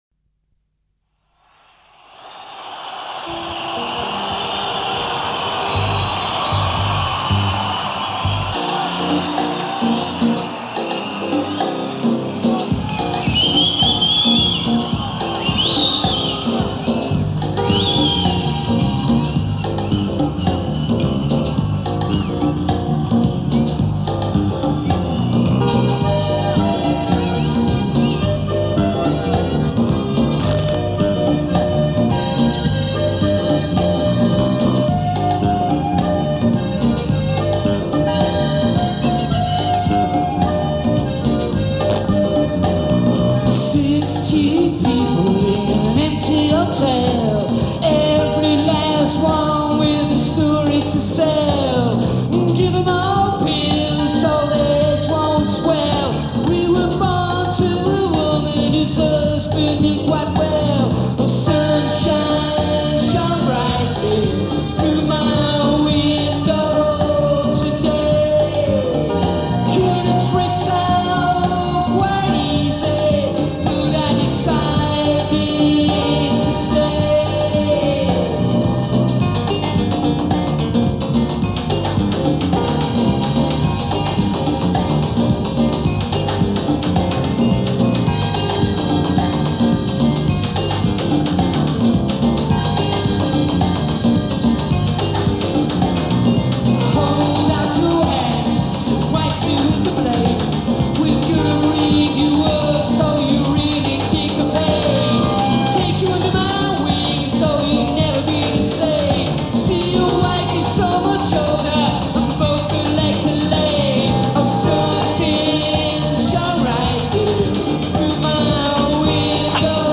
Live At Wembley Arena, London
lead singer